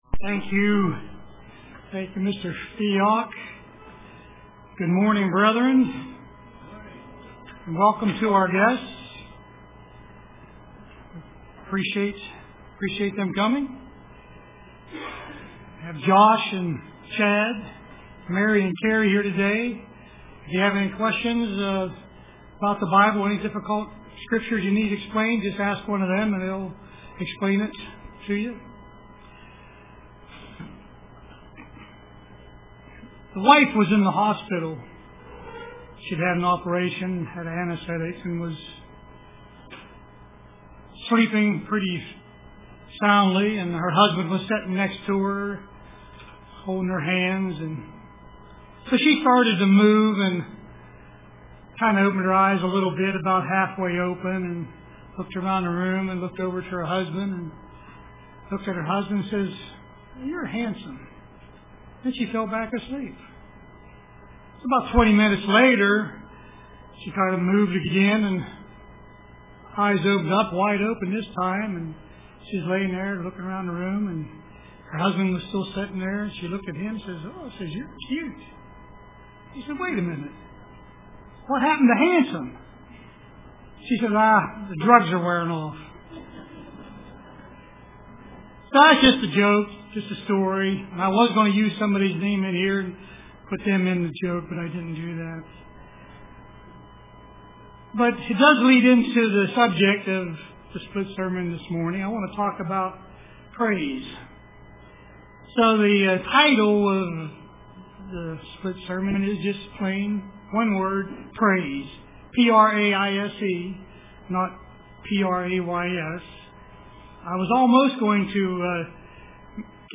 Print Praise UCG Sermon Studying the bible?